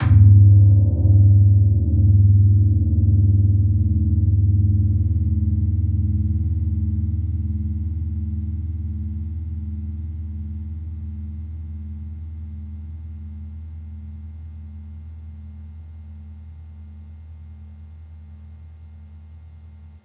Gong-G#1-f.wav